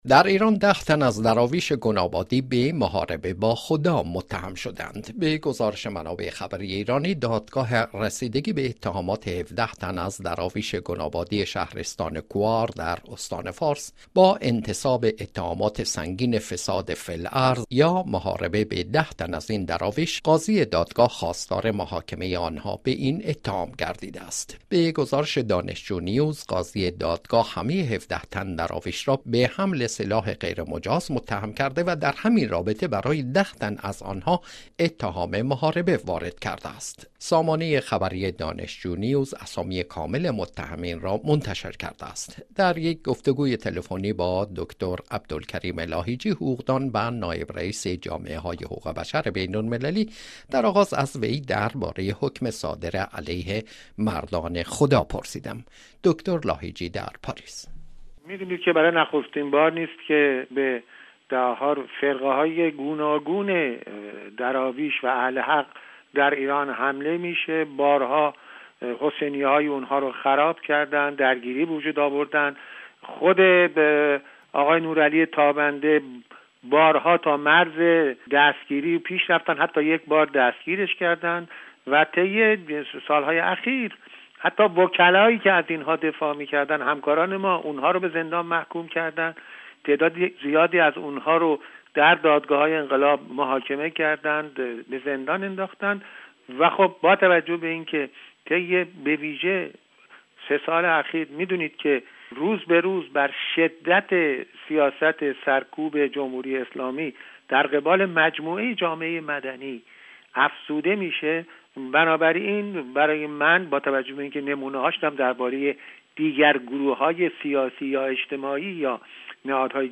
گفتگوی
دریک گفت وگوی تلفنی